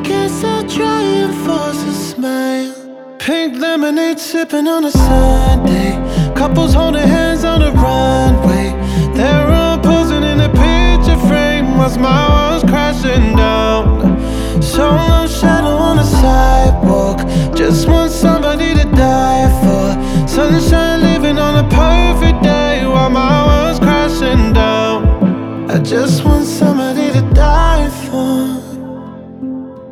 • Pop
is a piano-forward ballad